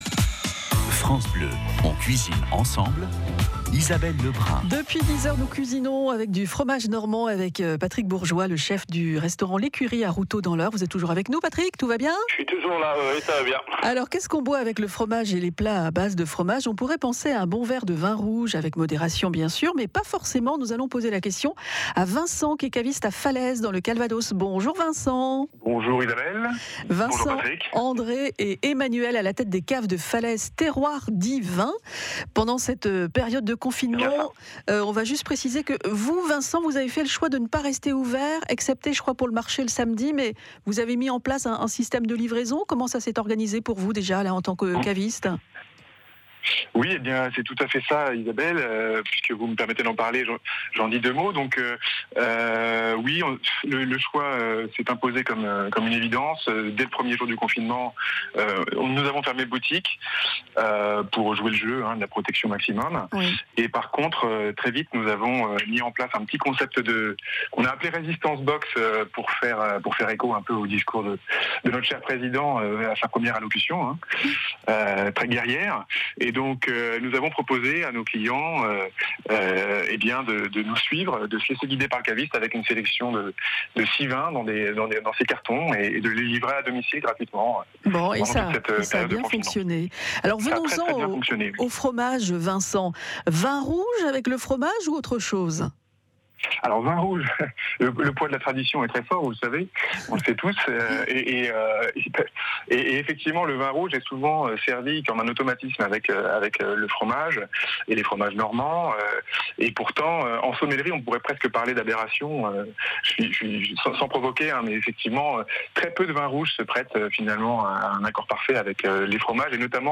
Intervention FranceBleu du 8 mai
FranceBleu_Normandie-EXTRAIT_CUISINE_VIN.mp3